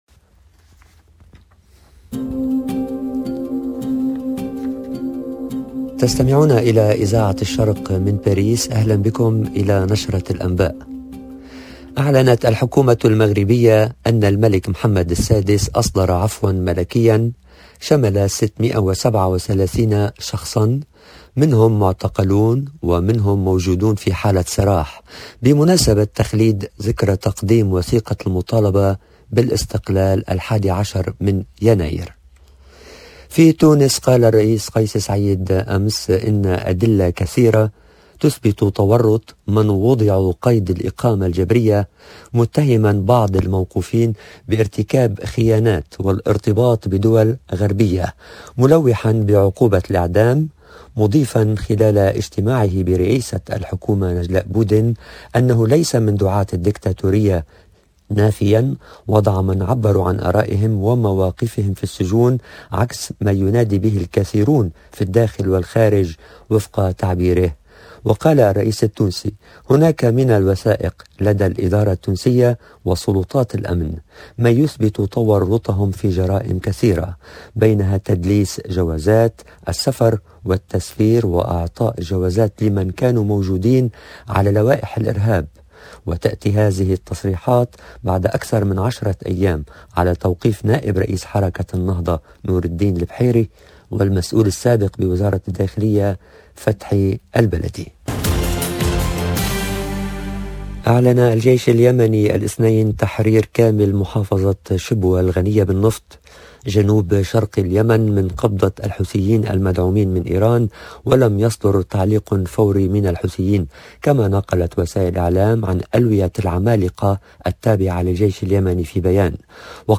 LE JOURNAL DE MIDI 30 EN LANGUE ARABE DU 11/01/22